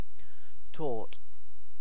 Ääntäminen
Synonyymit delict Ääntäminen US UK Tuntematon aksentti: IPA : /ˈtɔː(r)t/ Haettu sana löytyi näillä lähdekielillä: englanti Käännöksiä ei löytynyt valitulle kohdekielelle.